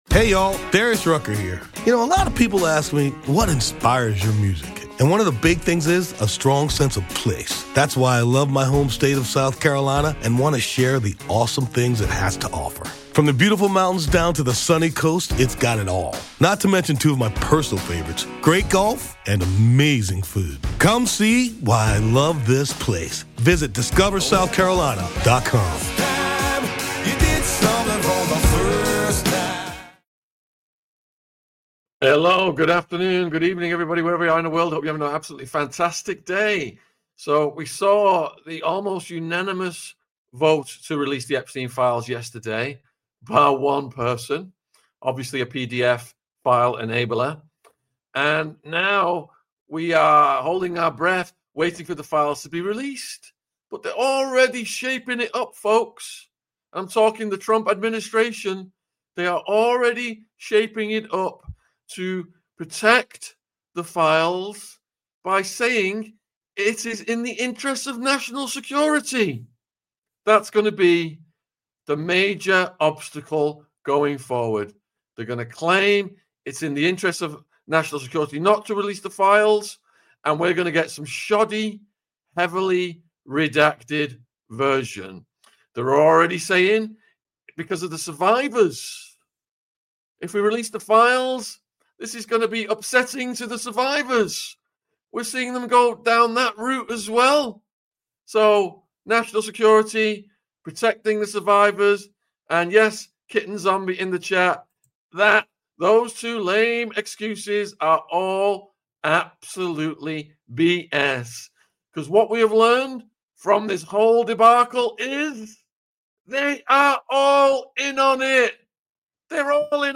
Epstein Files Call In Show - Trump MAGA Clinton Maxwell Virginia CONGRESS | AU 515